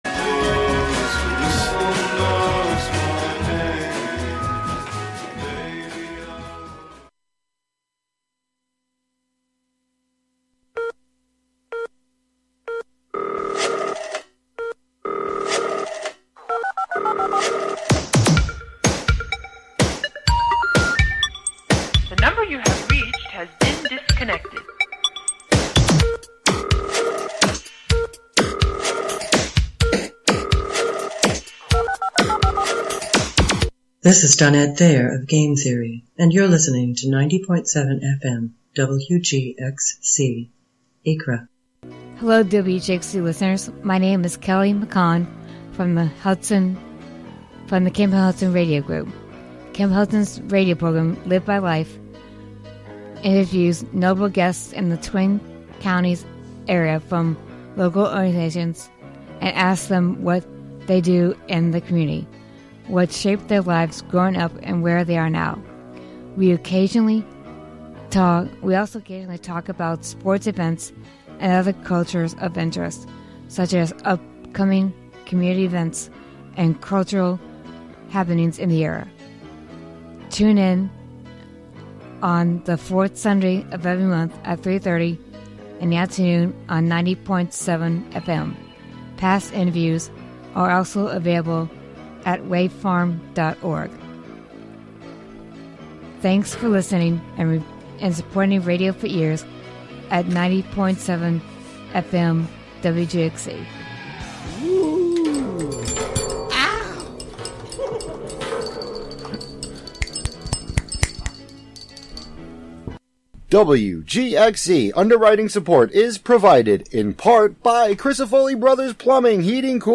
American Tarragon touches down onto the WGXC airwaves for its inaugural broadcast - blissed out recordings, musings, and live vinyl drops